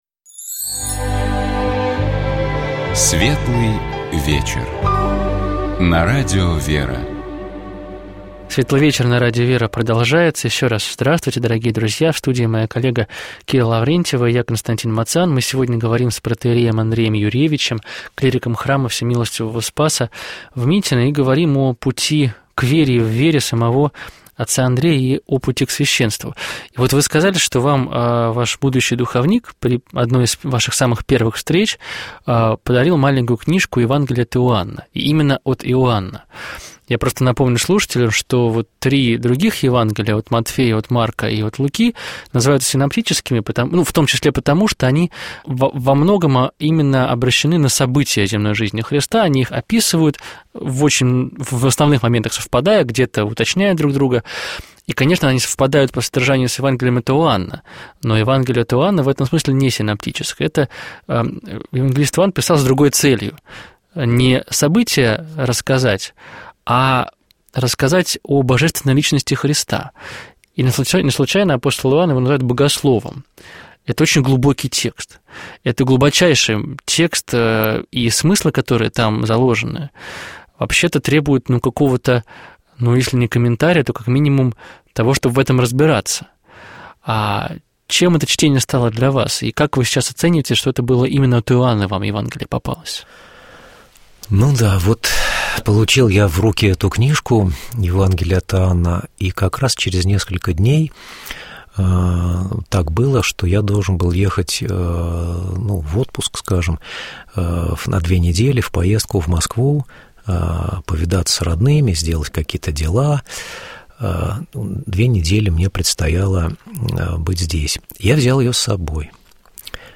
Мы говорили с нашим гостем о его поиске Бога, о пути к вере и о выборе священнического служения.